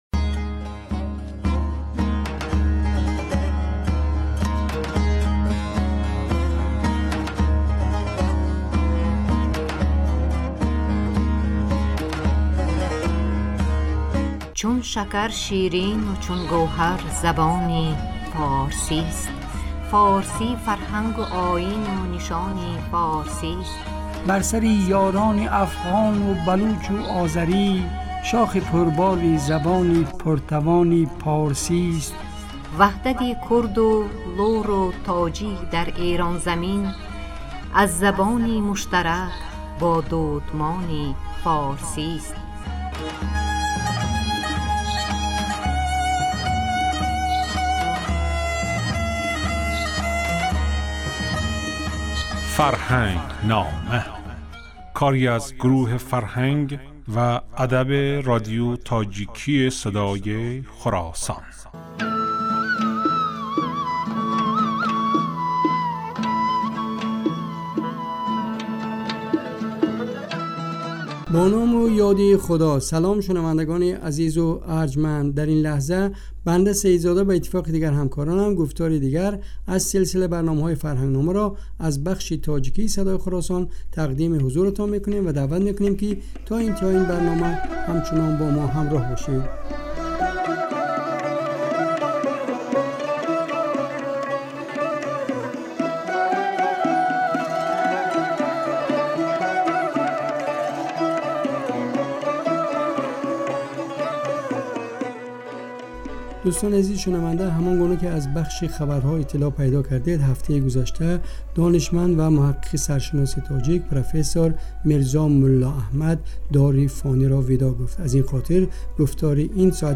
Ин гуфтор ҳар ҳафта рӯзи сешанбе, дар бахши субҳгоҳӣ ва шомгоҳӣ аз Садои Хуросон пахш мегардад.